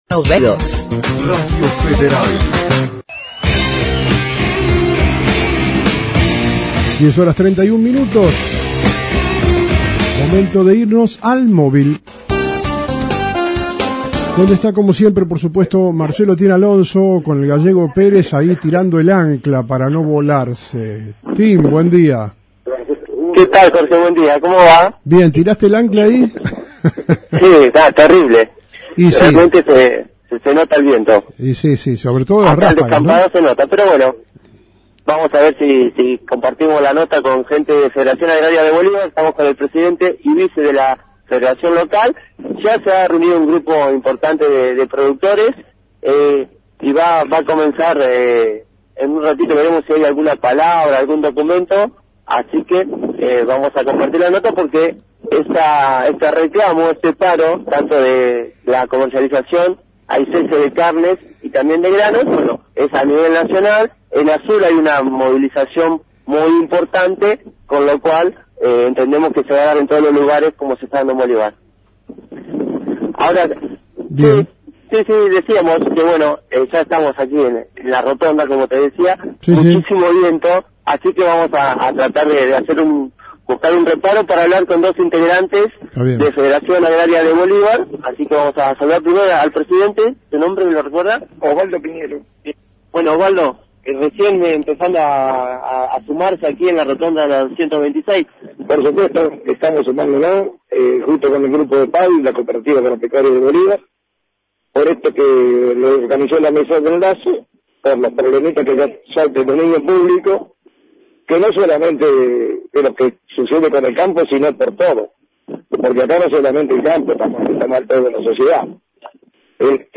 ENTREVISTA A REFERENTES LOCALES